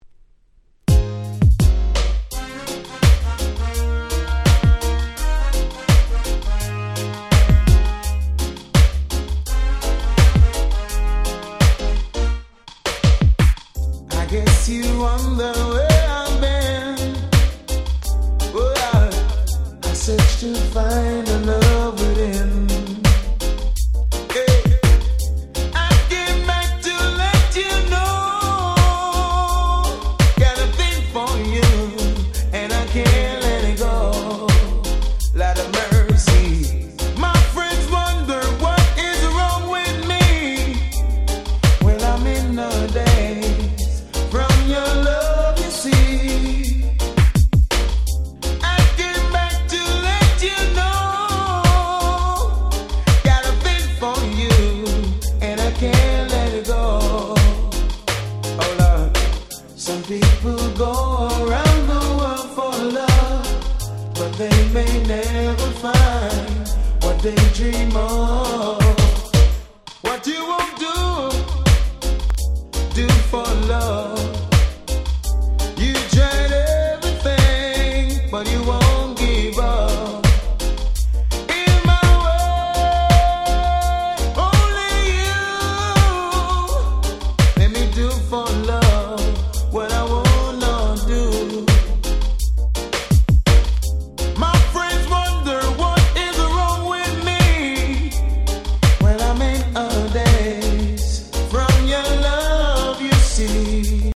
全て有名曲のLovers Reggaeカバー！